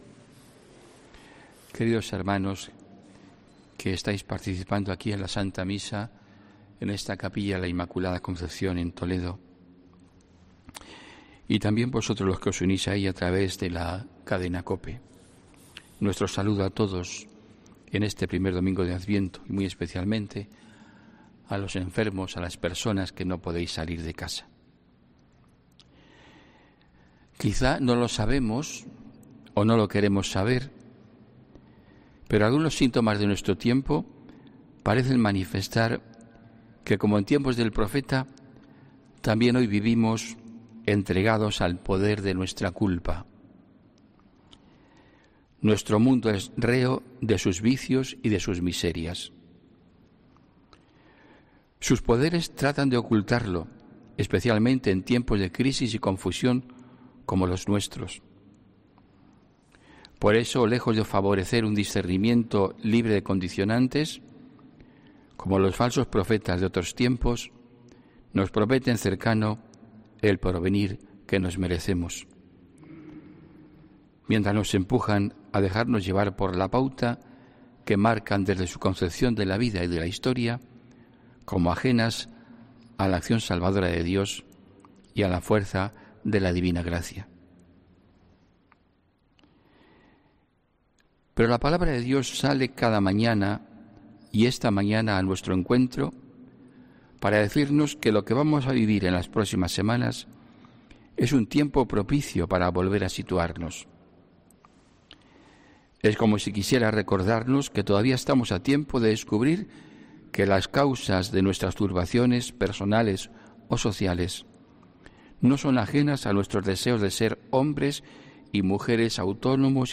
HOMILÍA 29 NOVIEMBRE 2020